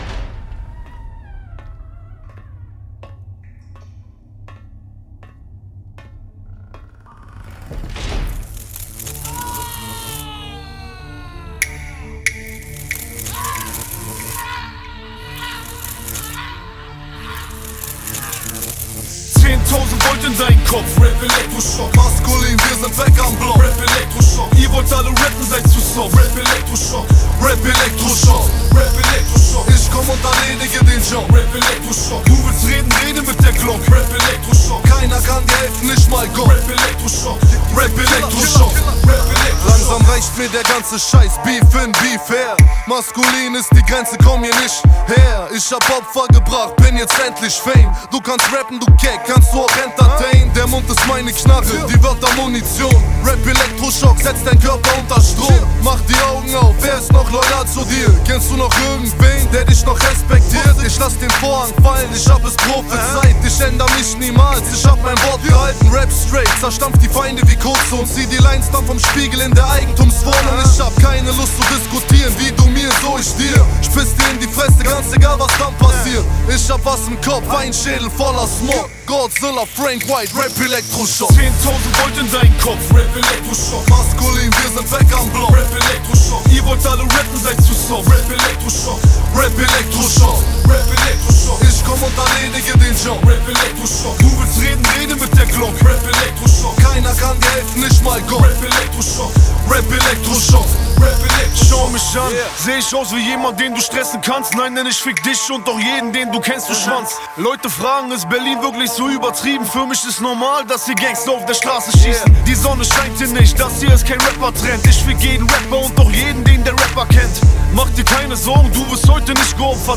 rap & hip-hop
deutsch rap